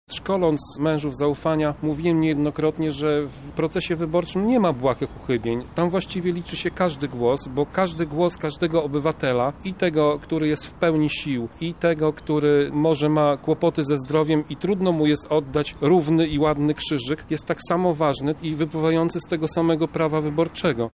Radny Pitucha wymienia między innymi zaniedbania przy stemplowaniu kart przed rozpoczęciem głosowania.